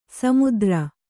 ♪ samudra